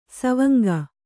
♪ savanga